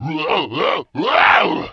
Werewolf Death 6 Sound
horror